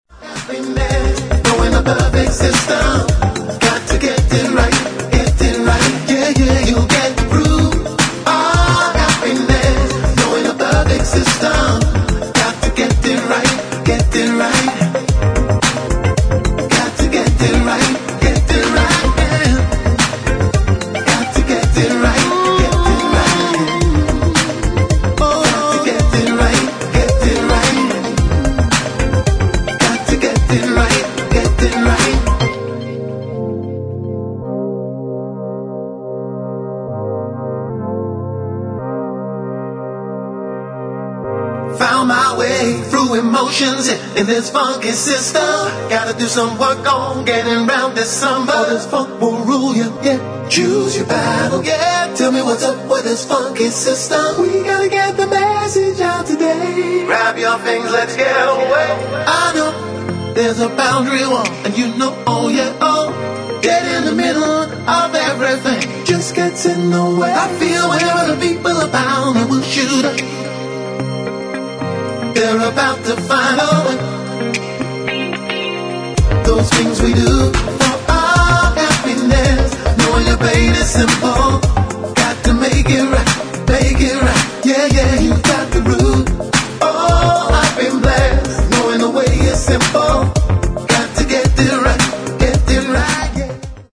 [ DISCO ]